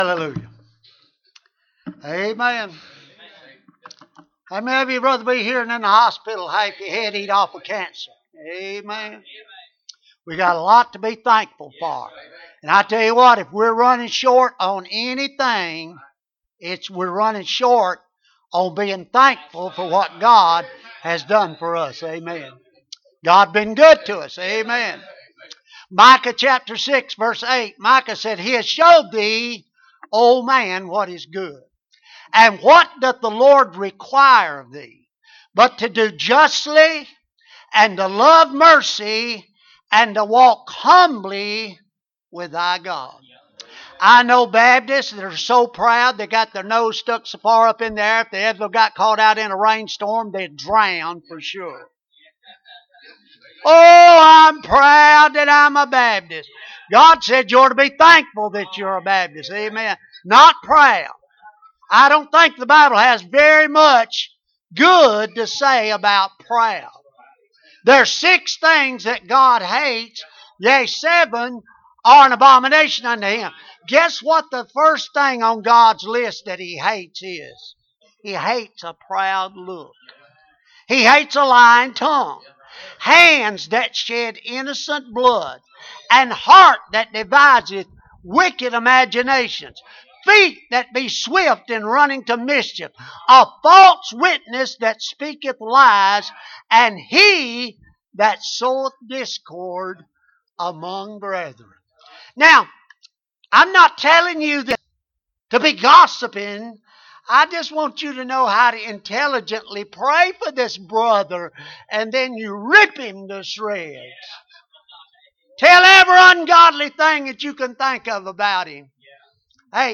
2020 Bible Conference Service Type: Bible Conference Preacher